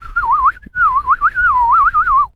pgs/Assets/Audio/Animal_Impersonations/bird_sparrow_tweet_02.wav at master
bird_sparrow_tweet_02.wav